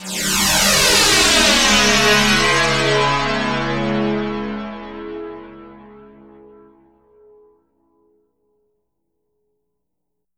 Index of /90_sSampleCDs/E-MU Producer Series Vol. 4 – Denny Jaeger Private/Effects/Piano FX